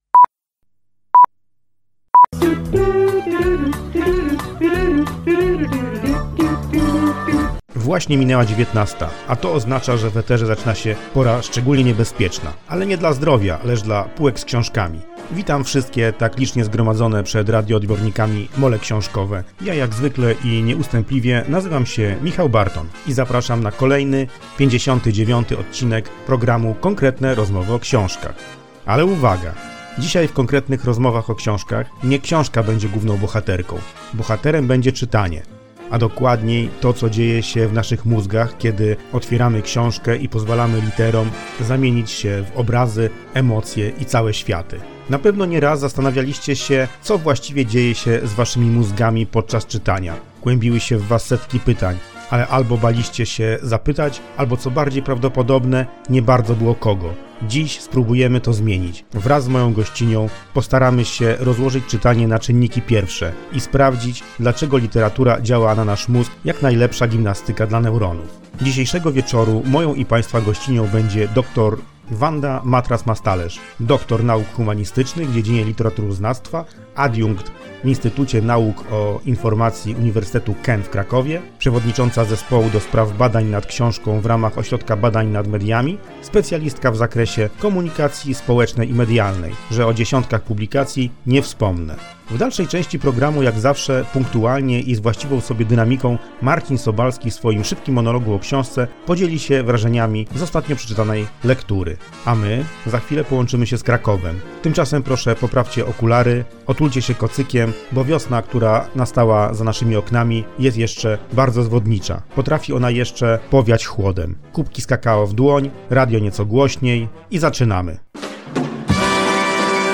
krótkim, dynamicznym monologiem o nieco dziś zapomnianym gatunku literackim – westernie.